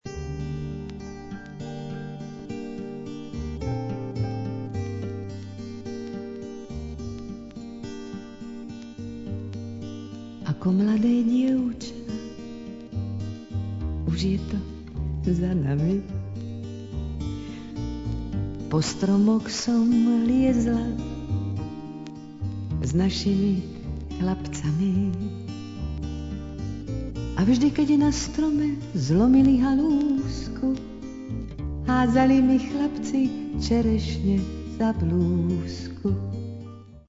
the most famous Czech chanson singer.